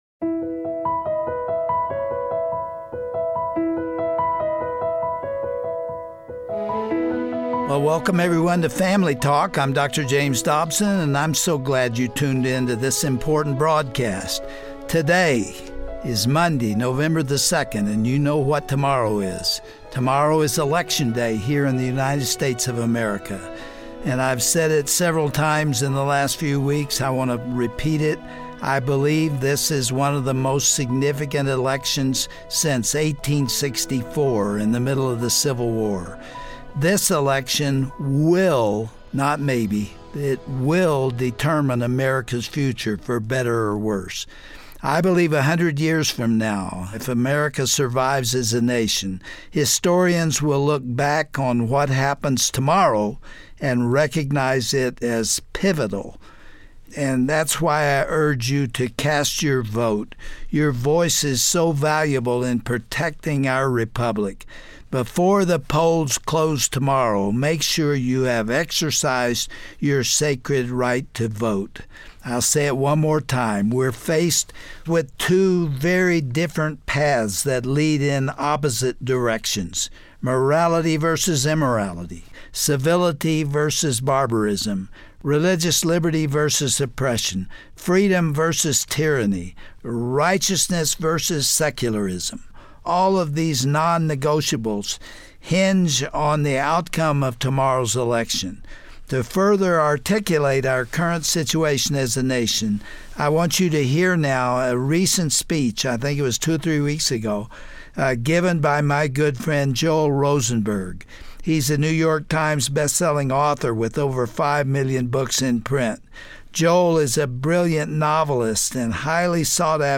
Celebrated author Joel Rosenberg ponders that question in a recently recorded speech. He believes that the unsettling times we are living in today is God's way of re-directing our attention back to Him, and warns that judgement is imminent because of America's embrace of sin.